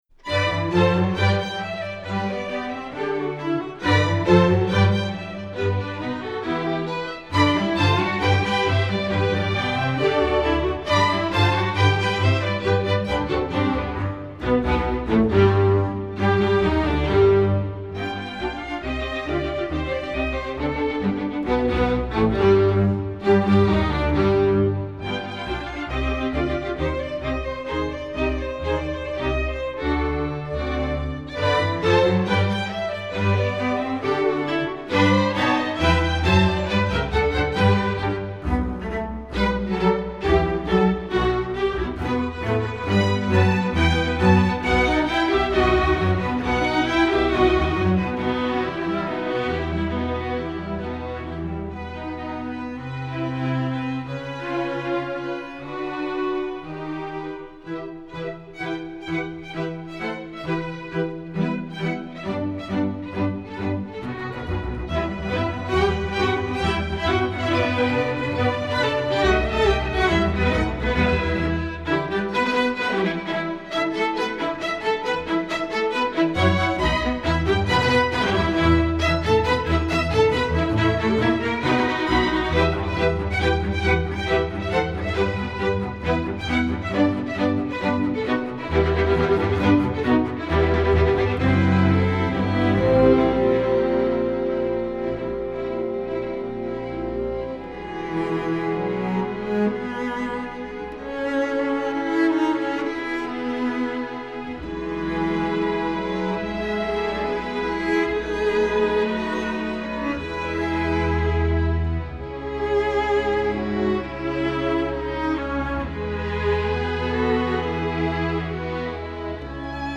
Instrumentation: string orchestra
classical